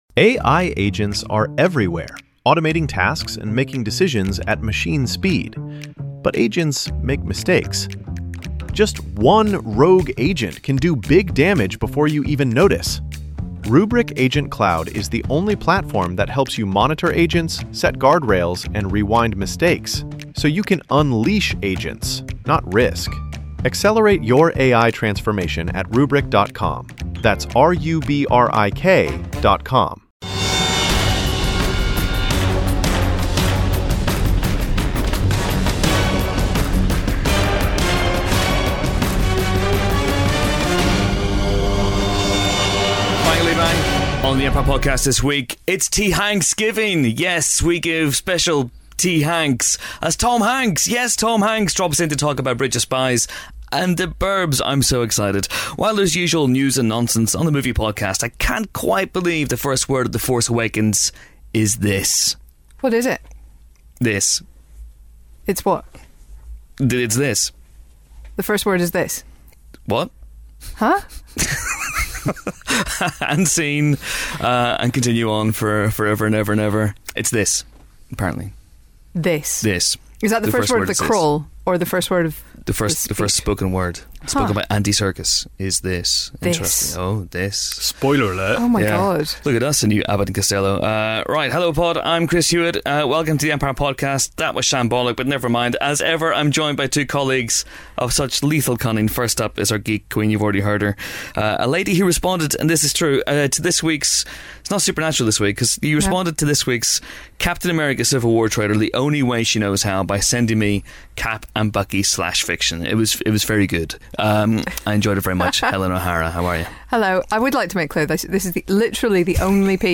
This week, young up-and-comer Tom Hanks stops by to talk about his Bridge Of Spies.